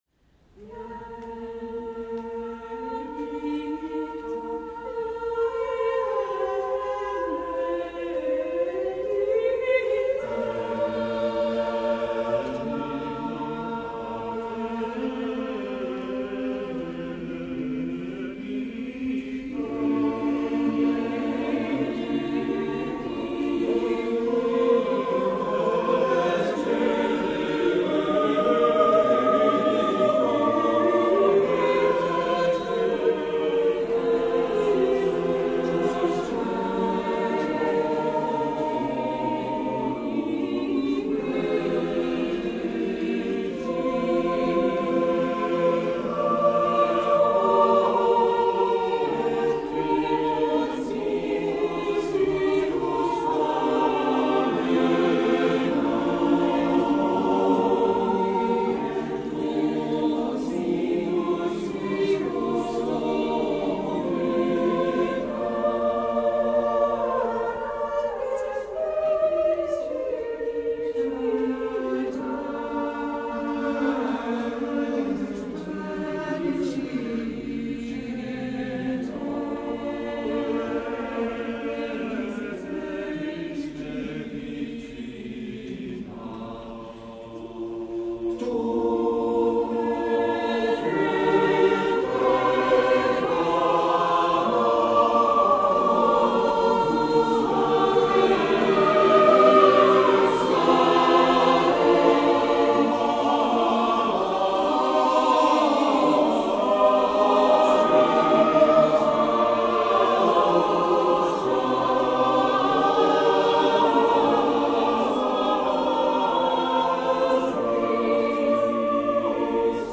The Renaissance Street Singers' Annual Loft Concert, 2006